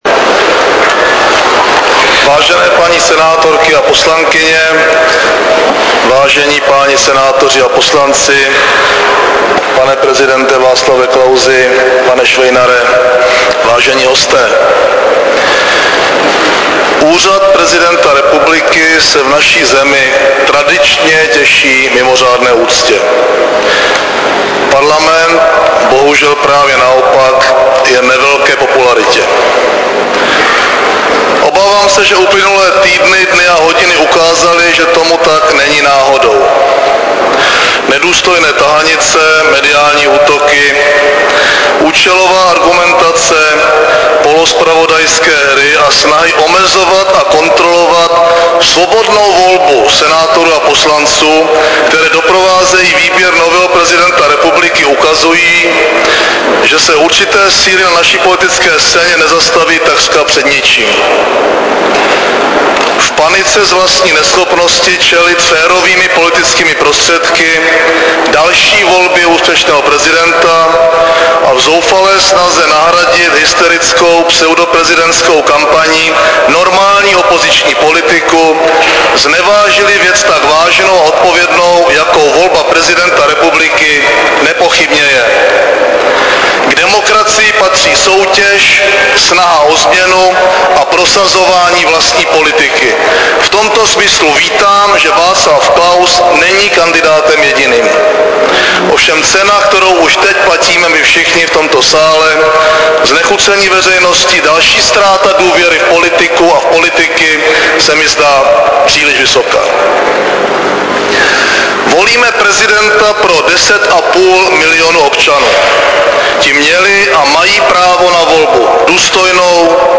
Předseda vlády a předseda ODS Mirek Topolánek ve Španělském sále Pražského hradu pronesl projev u příležitosti volby hlavy státu.
projev_premi_ra_p_i_volb__prezidenta.mp3